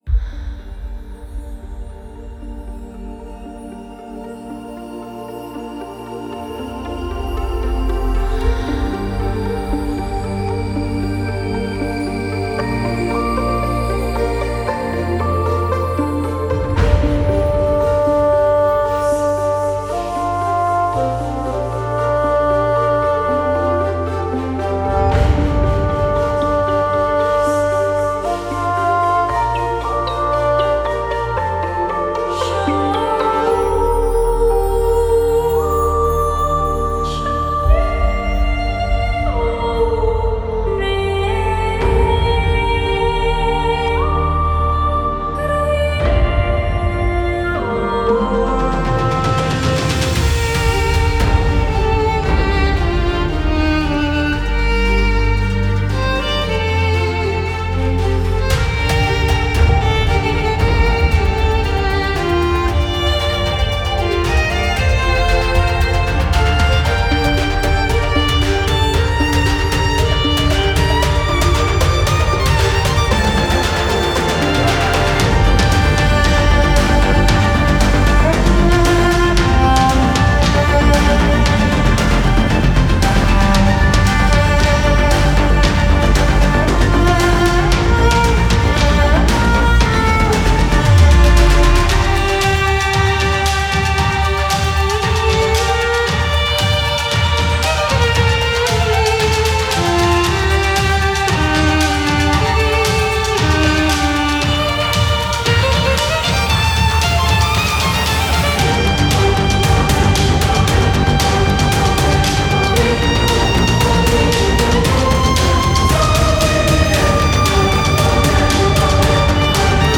史诗气势音乐